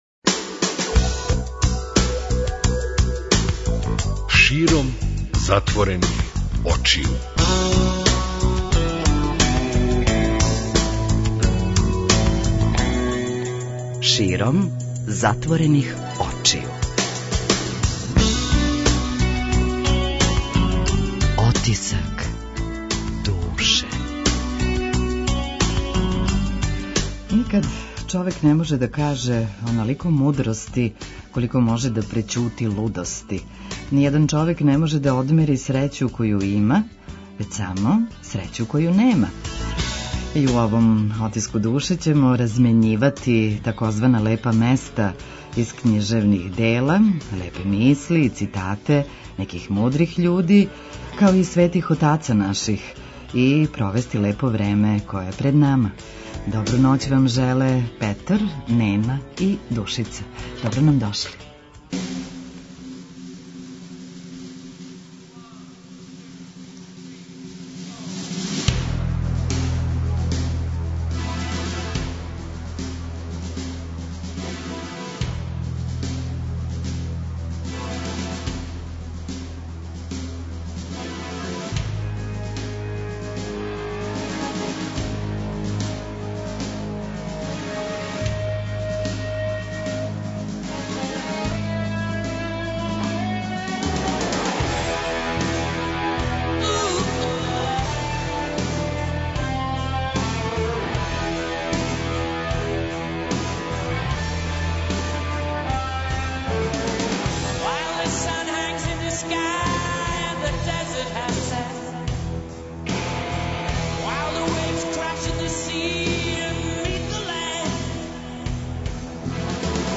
(Момо Kапор) Ноћас читамо одломке из књижевних дела која су нас дотакла.